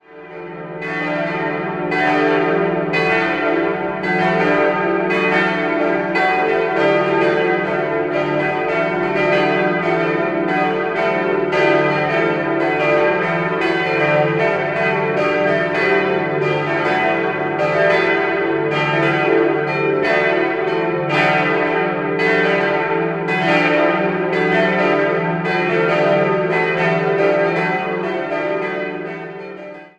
Nach Kriegszerstörungen wurde sie in einfacherer Form wieder aufgebaut. 4-stimmiges Geläut: d'-e'-fis'-a' Die drei großen Glocken wurden 1955, die kleine 1924 von Rincker in Sinn gegossen.